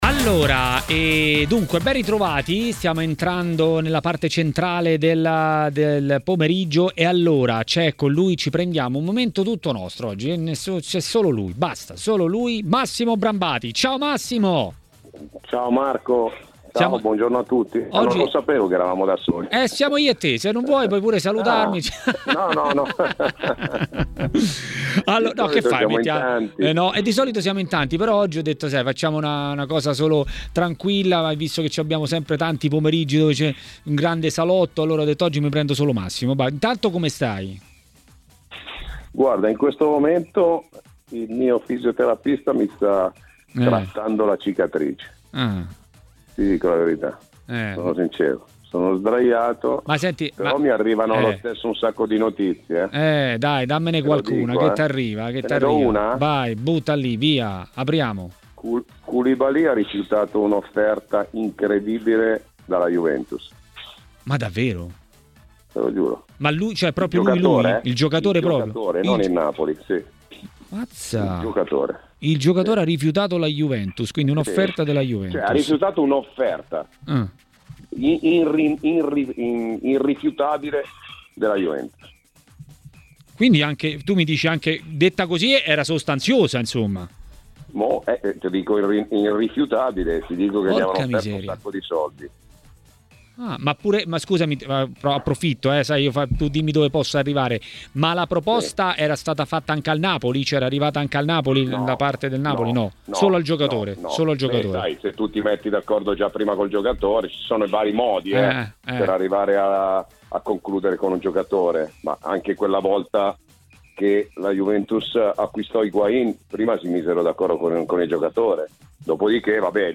Ospite